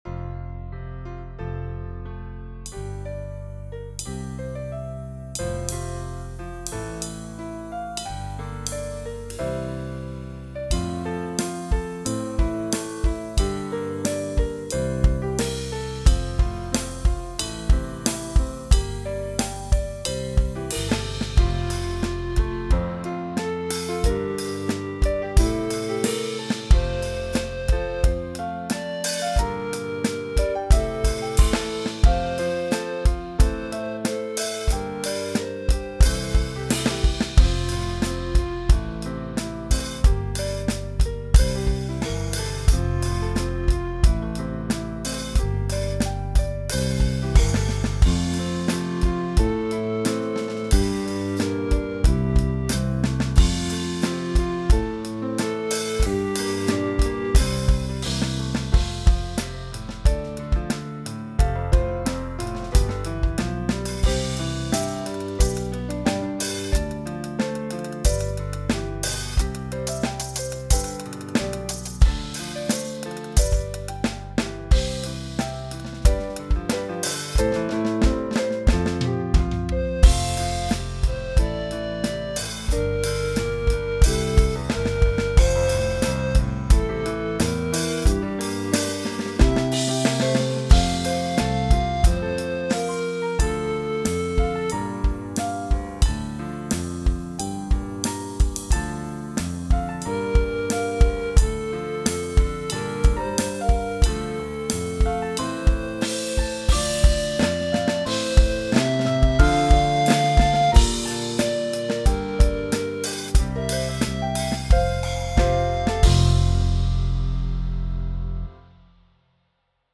• Vigil (instrumental)